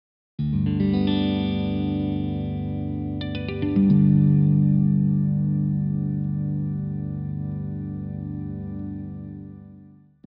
D-G-D-G-B-D
Gives a more ambiguous resonance than the other ‘main’ maj-chord tuning – Open D – as its major triad is arranged in ‘inverted’ fashion, with the 5th (D) in the bass rather than the G root (which is on 5str).
This versatility arises from its mix of spacey low drones and clustered high tones: the former (6-5-4-3str) bring reassuring depth and power, while the latter (3-2-1str) allow for close-voiced chords and dense double-stop melodies.